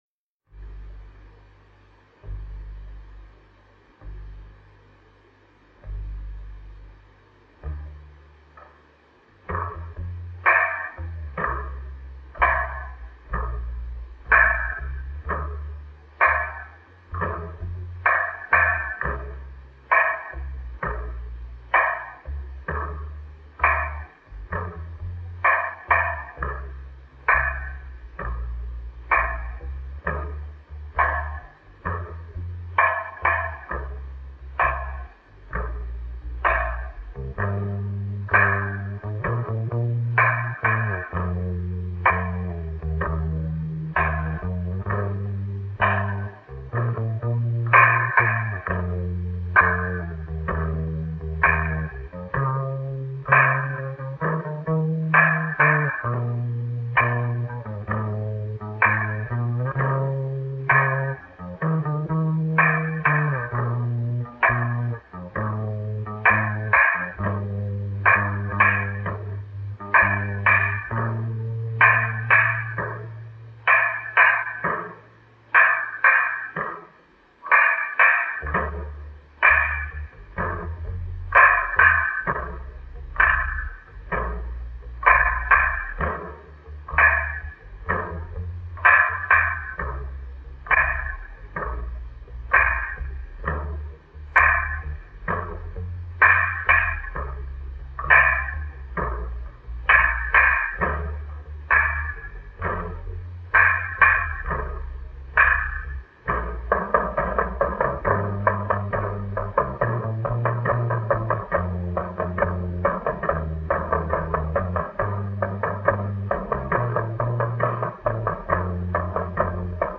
Записано в феврале 1973 года в ЛГУ на факультете ПМиПУ
Очень смешно, но очень плохо записано".